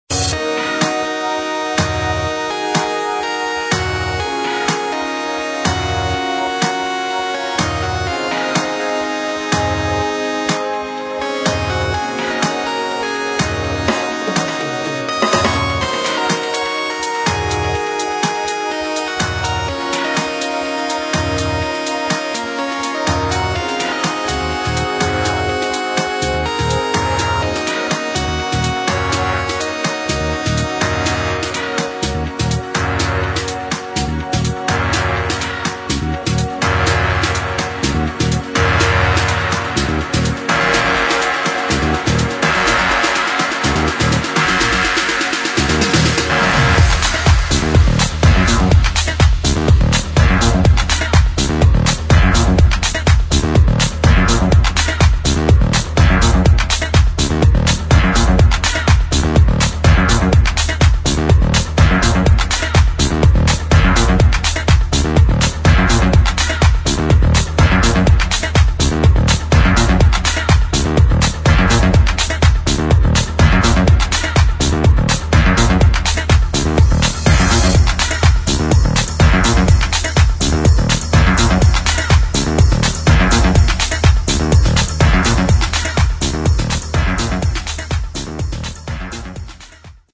[ DEEP HOUSE / TECH HOUSE ]